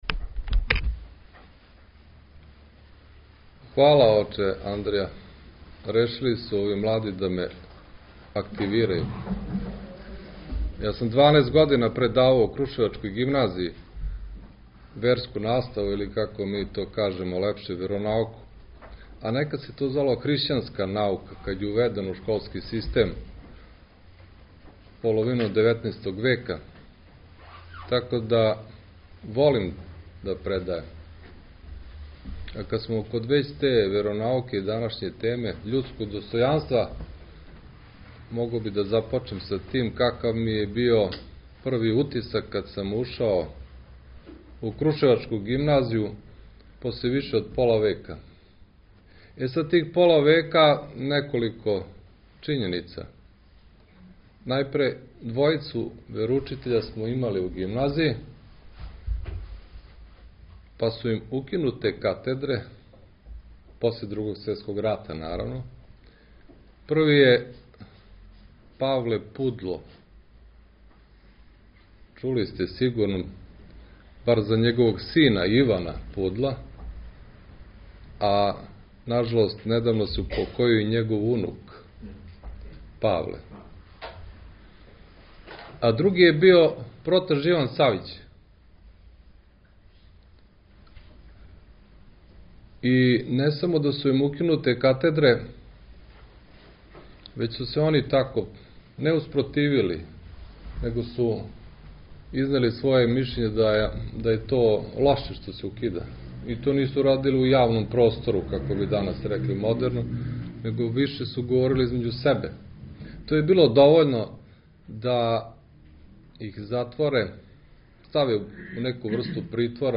По благослову Његовог Преосвештенства епископа крушевачког Господина Давида братство храма је организовало циклус великопосних предавања при храму Светог Ђорђа у Крушевцу.
Звучни запис предавања